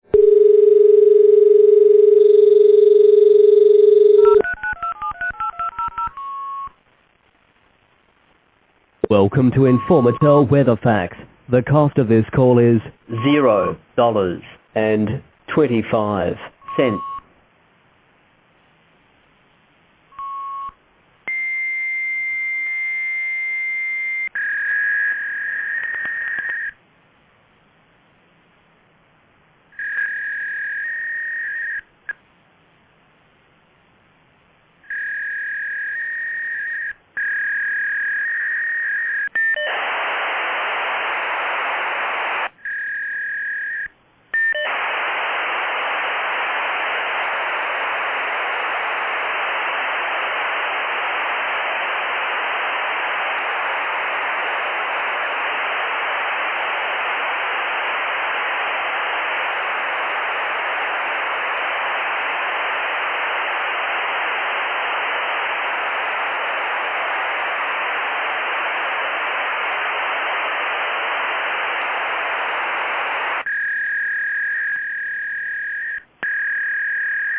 Listening to the sound below - you can hear the adaptive echo canceller totally screwing up the HDLC data at the beginning.